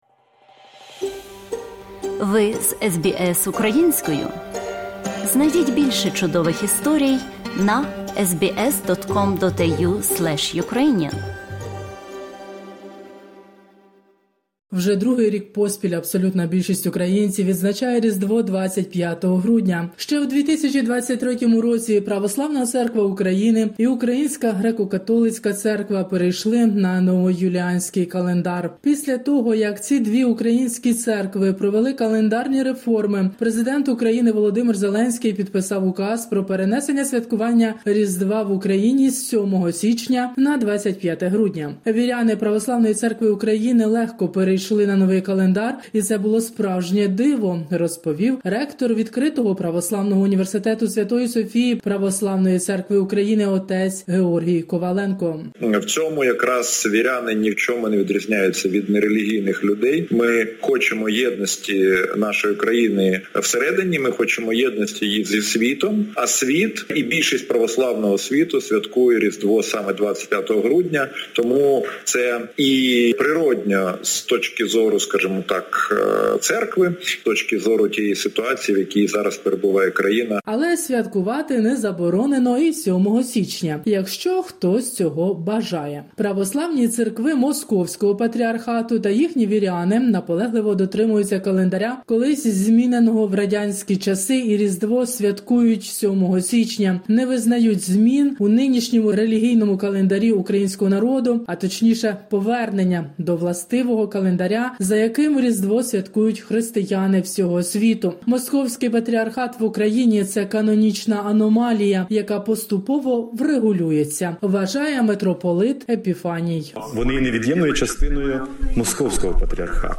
Різдво в час війни. Репортаж із Києва